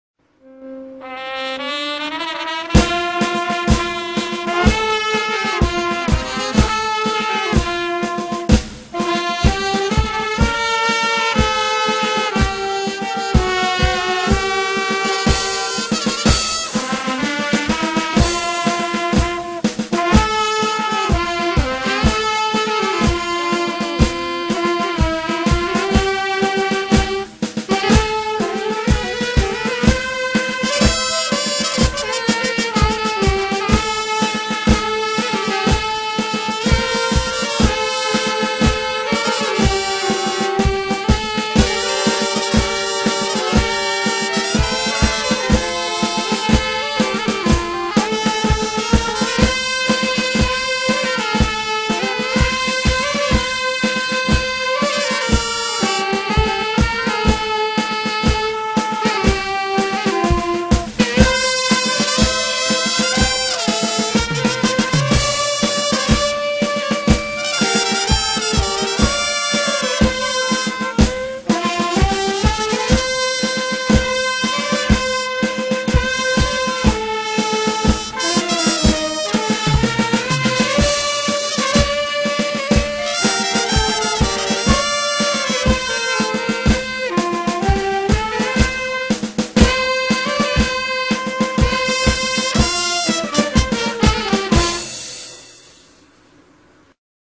Durante la processione del Carro trionfale dell'Immacolata,la Banda musicale di Torre del Greco esegue costantantemente brani musicali molto cari al popolo torrese: